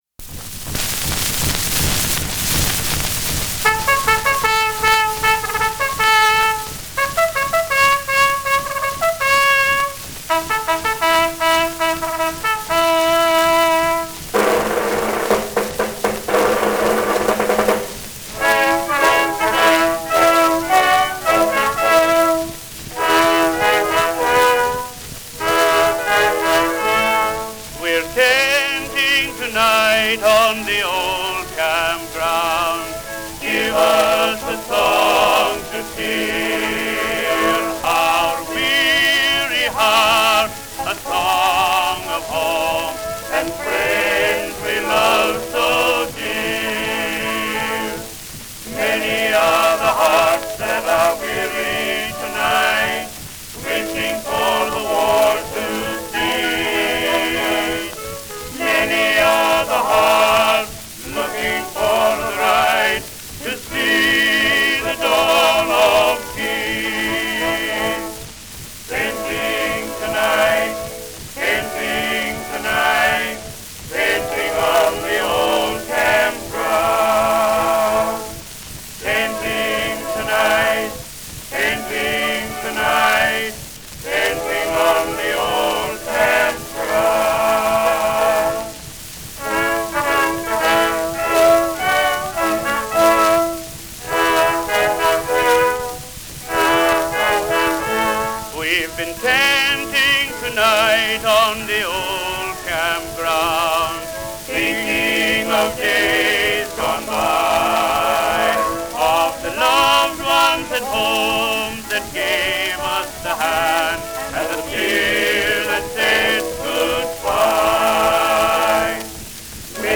Peerless Quartet.
Lakeside Indestructible Cylinder Record: 1079.
Peerless Quartet,
Popular music—1911-1920.